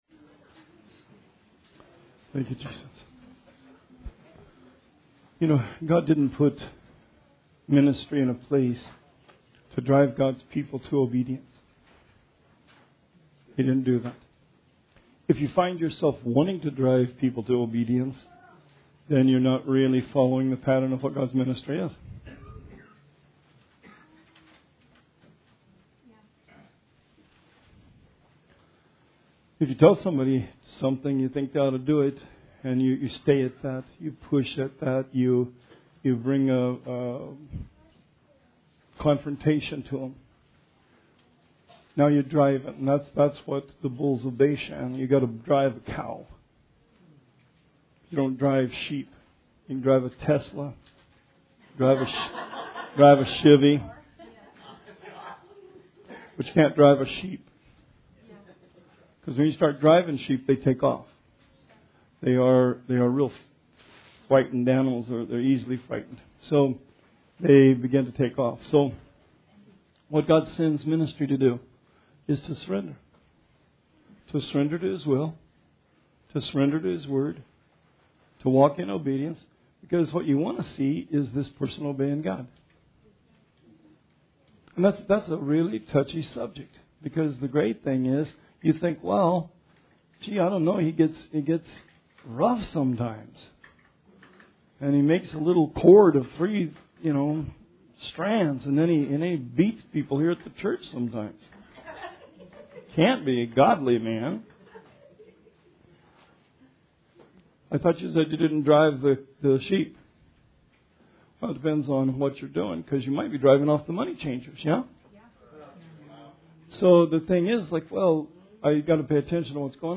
Bible Study 7/31/19